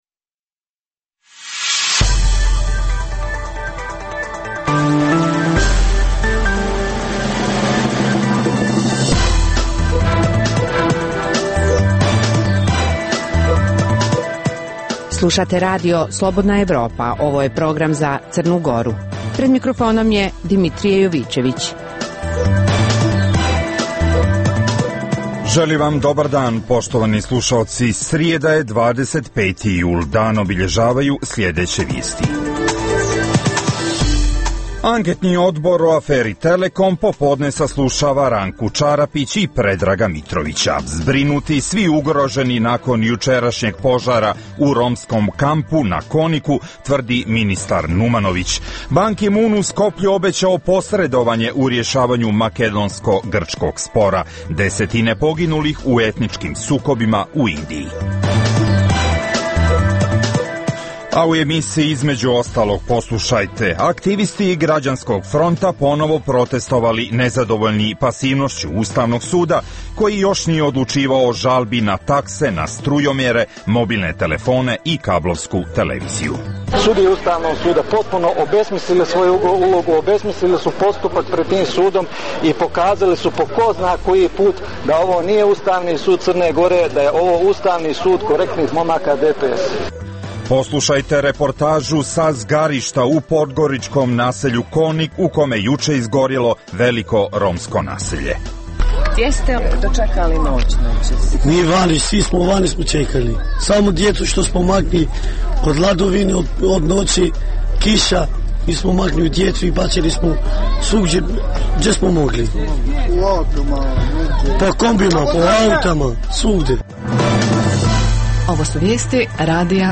U emisiji poslušajte: - Aktivisti Građanskog fronta ponovo protestovali nezadovoljni pasivnošću Ustavnog suda koji još nije odlučivao o žalbi na takse na strujomere, mobilne telefone i kablovsku televiziju - Poslušajte reportažu sa zgarišta u podgoričkom naselju Konik u kome je juče izgorjelo veliko romsko naselje